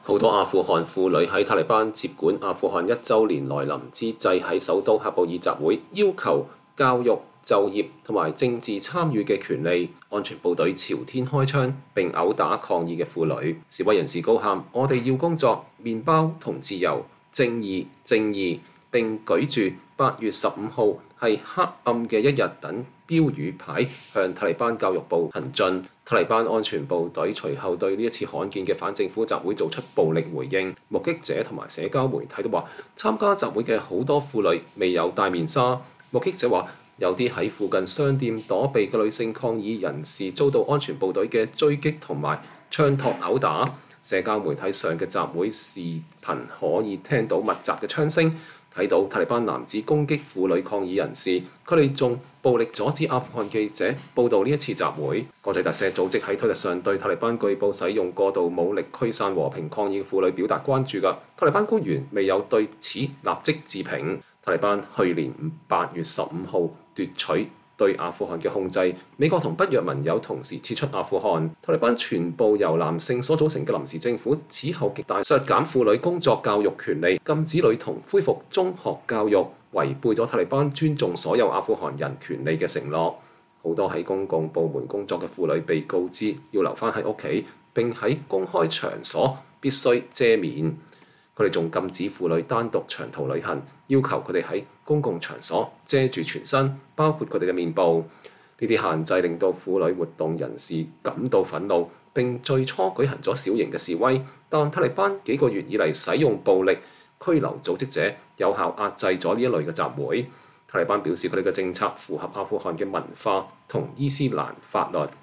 社交媒體上的集會視頻可以聽到密集的槍聲，看到塔利班男子攻擊婦女抗議人士。